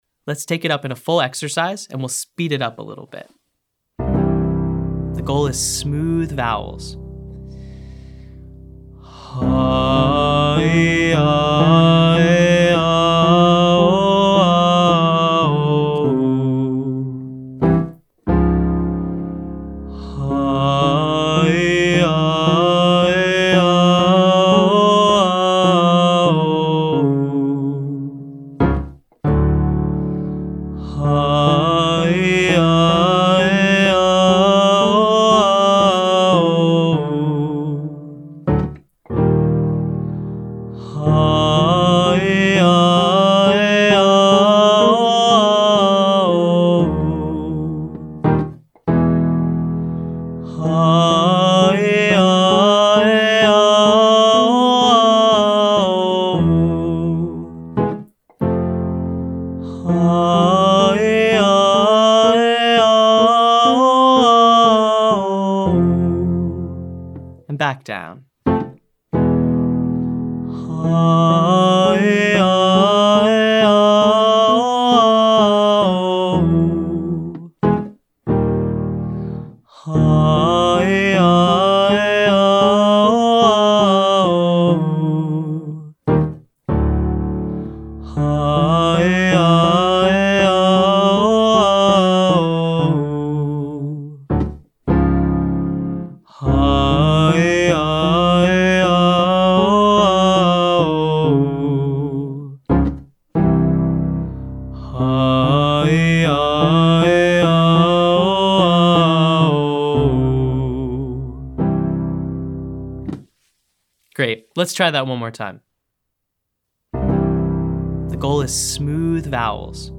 Exercise 2: Huh ee, Uh aye, Uh Oh, Uh Oh, oo (123, 234, 345, 4321)
Let’s combine the feeling of exercise 1 and 2 together with a gentle Hah slowly morphing to Aye and then Oh on a 1,2,3,2,1 pattern. Each note is about 4 beats long!